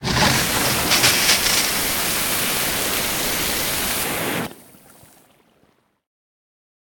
hose.ogg